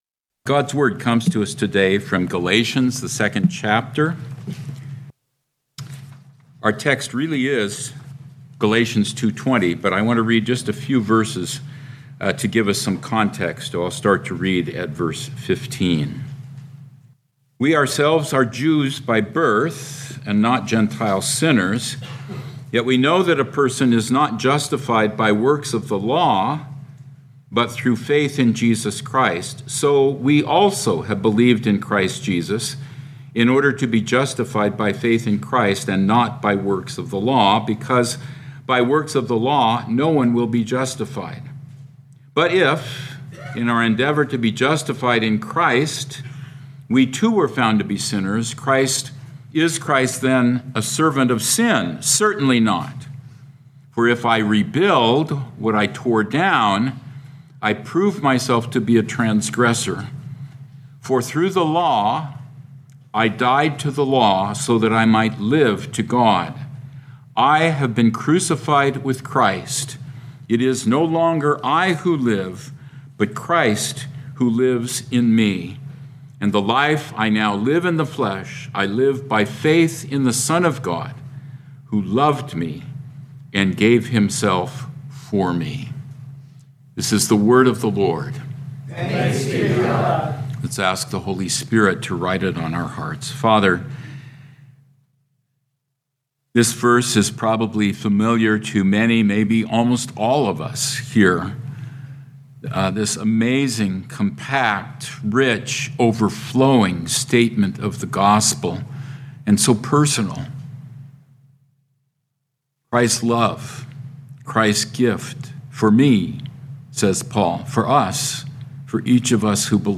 2025 Galations Evening Service Download